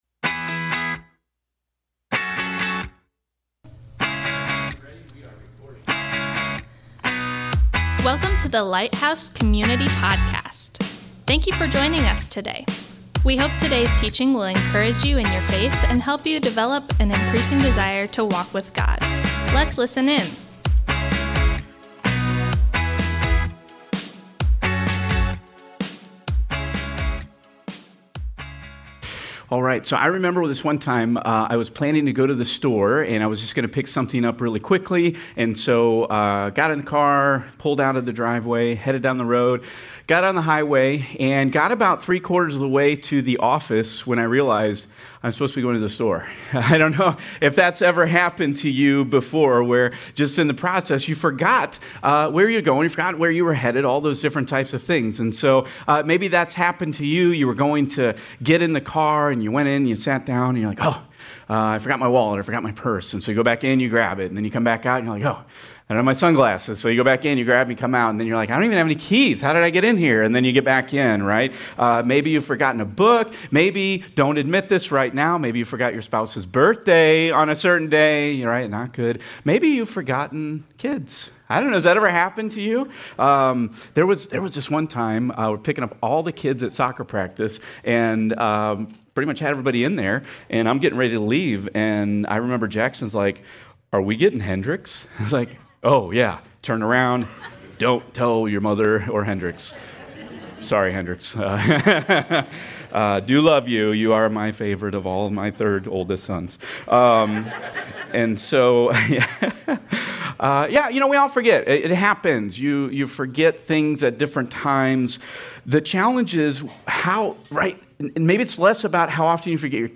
THE LORDS SUPPER | Stand Alone Teaching